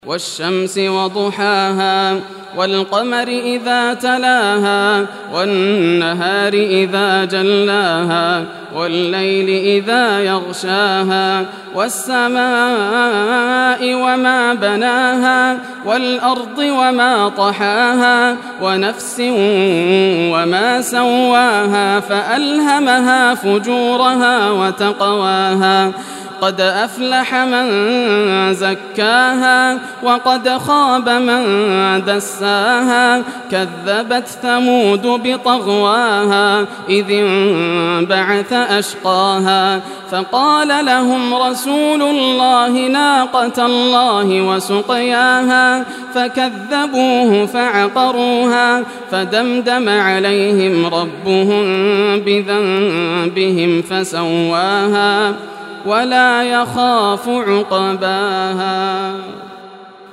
Surah Ash-Shams Recitation by Yasser al Dosari
Surah Ash-Shams, listen or play online mp3 tilawat / recitation in Arabic in the beautiful voice of Sheikh Yasser al Dosari. Download audio tilawat of Surah Ash-Shams free mp3 in best audio quality.